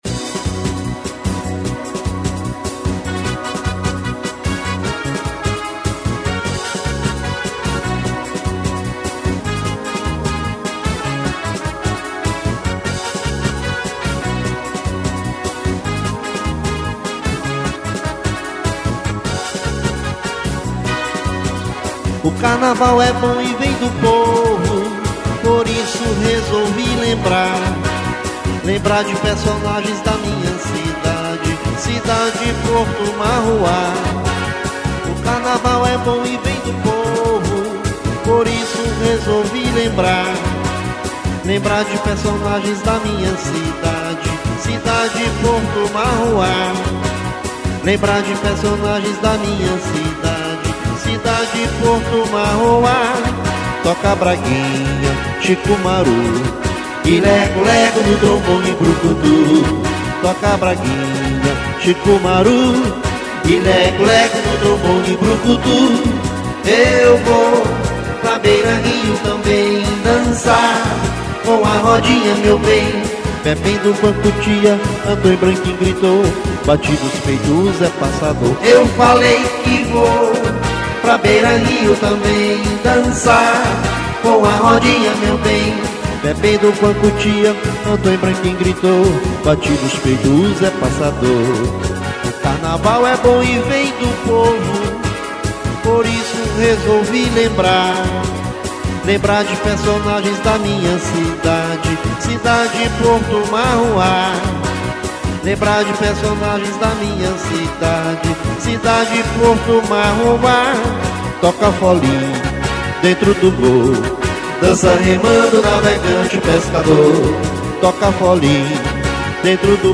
2544   03:42:00   Faixa: 3    Marcha